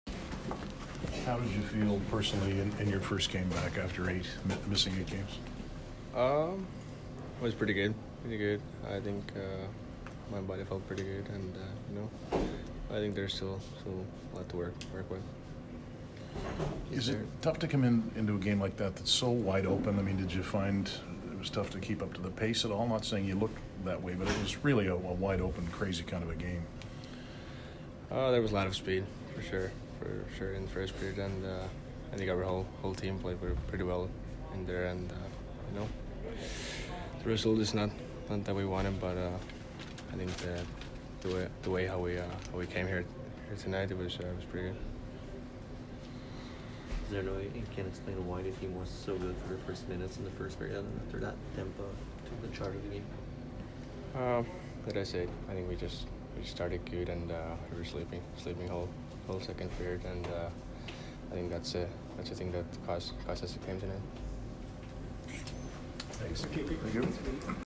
Kotkaniemi post-game 12/28